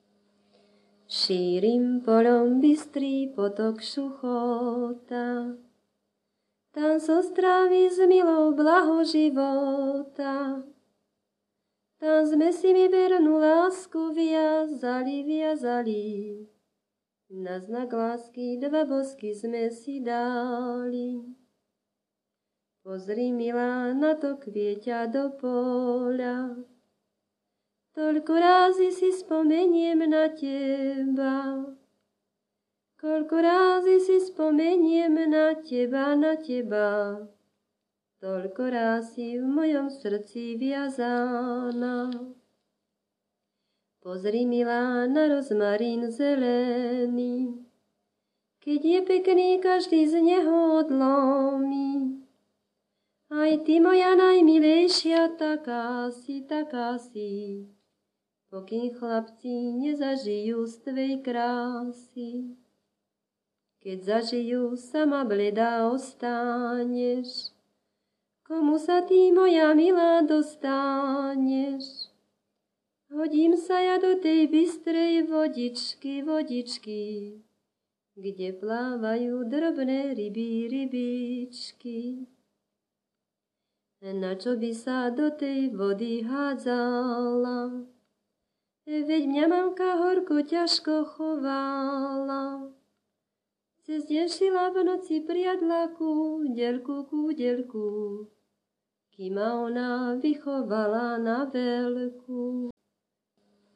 Popis sólo ženský spev bez hudobného sprievodu
Miesto záznamu Litava
Predmetová klasifikácia 11.7. Piesne pri tanci
Kľúčové slová ľudová pieseň
čardáš